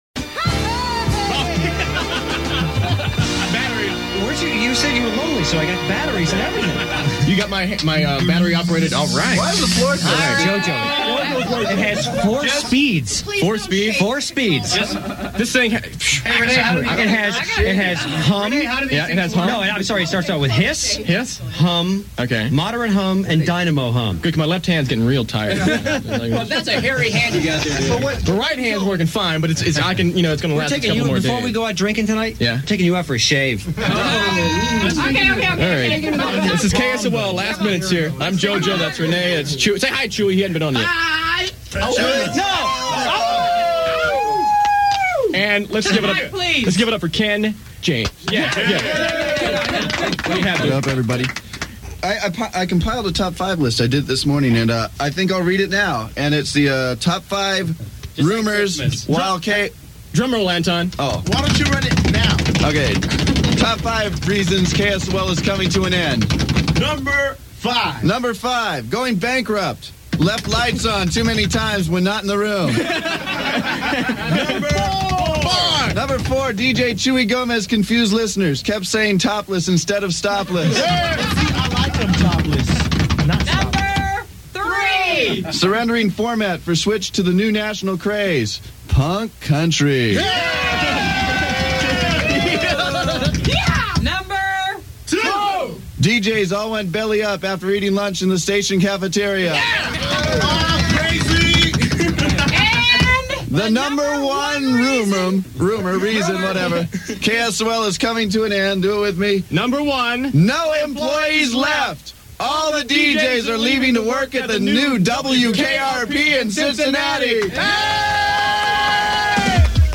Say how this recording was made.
107.7 KSOL Signs Off